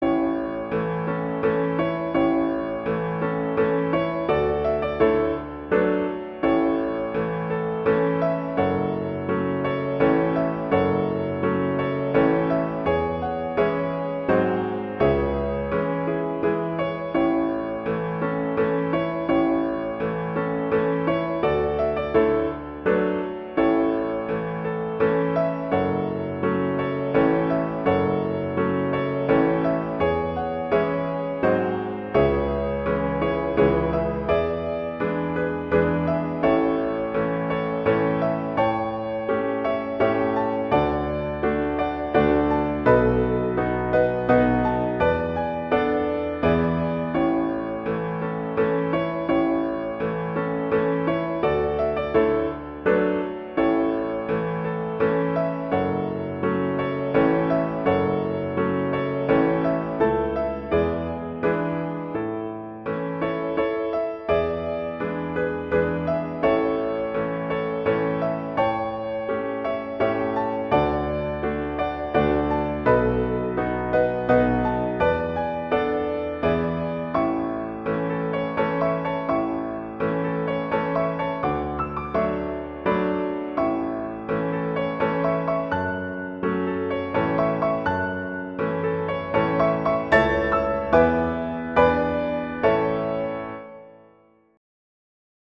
classical
A major
♩=84 BPM